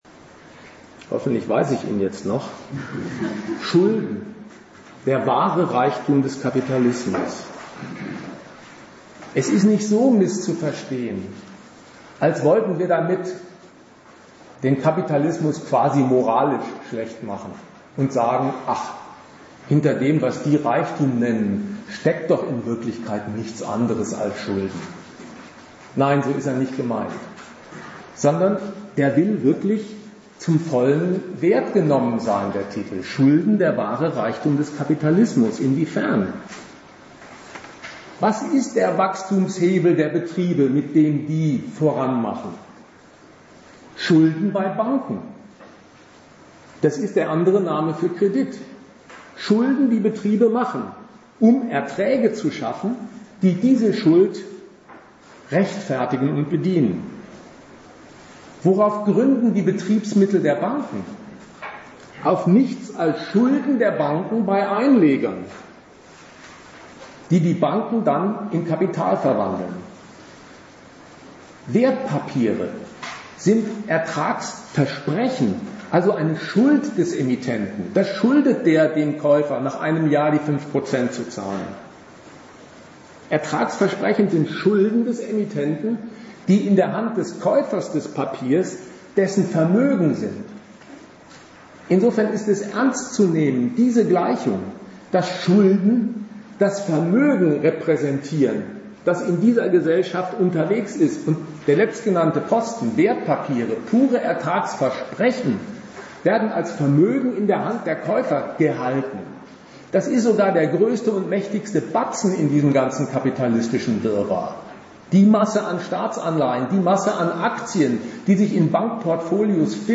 Ort Wien
Dozent Gastreferenten der Zeitschrift GegenStandpunkt